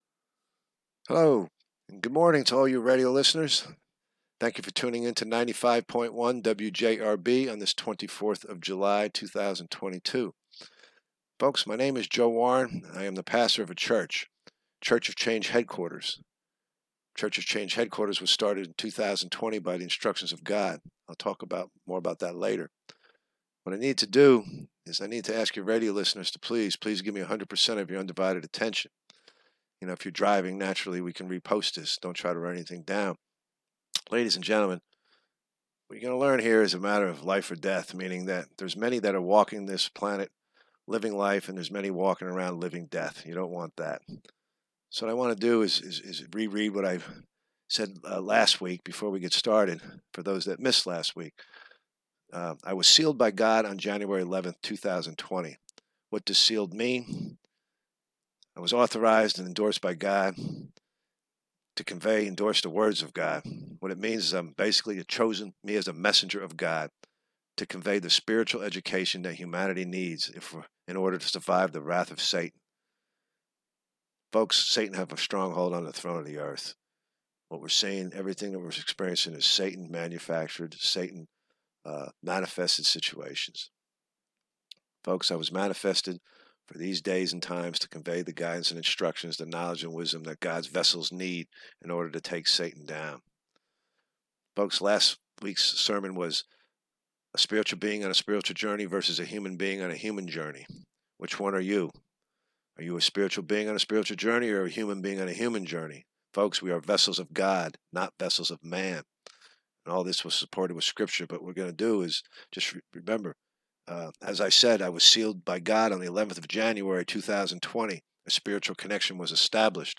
sermon #2
sermon-2.m4a